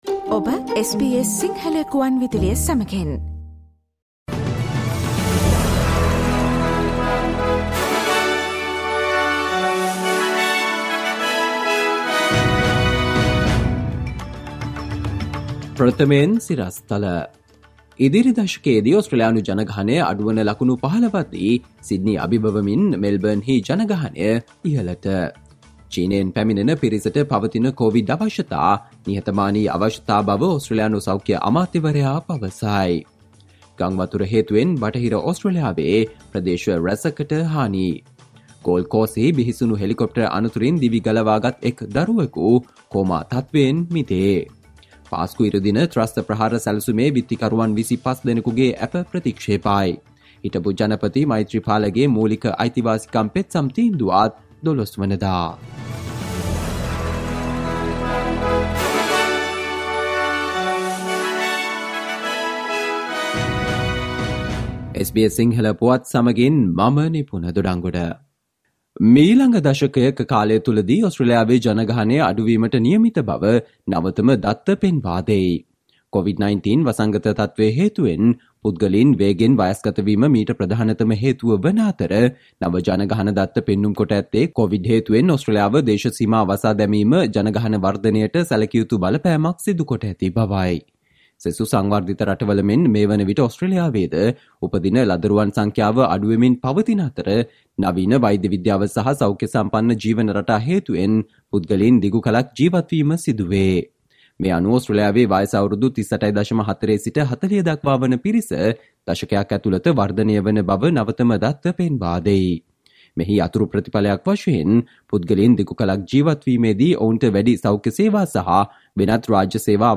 සවන්දෙන්න, අද - ජනවාරි 06 වන සිකුරාදා SBS ගුවන්විදුලි වැඩසටහනේ ප්‍රවෘත්ති ප්‍රකාශයට